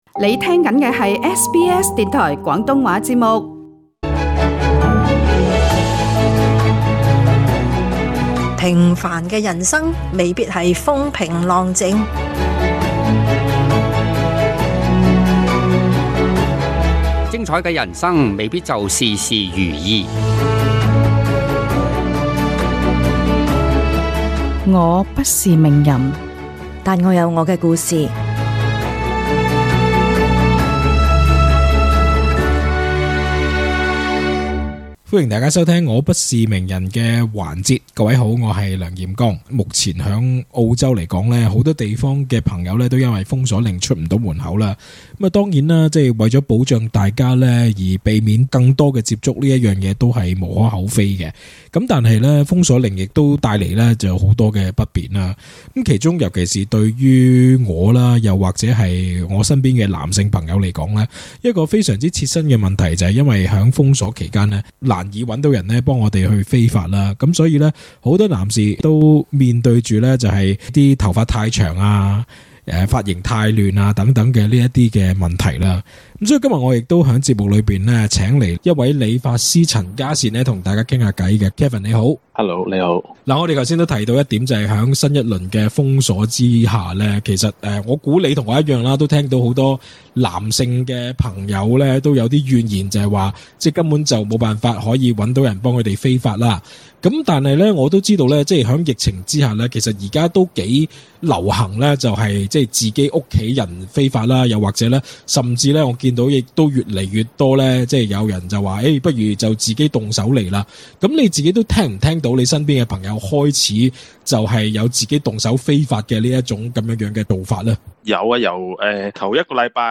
雖然新州政府經已推出解封路線圖，只要70%居民完成兩劑疫苗接種就會局部開放社區，包括讓市民出外理髮，但真正解封前，你又是否經已掌握到自己理髮的技巧？還沒有的話，SBS節目組請來一位髮型師教大家在家輕鬆理髮的竅門。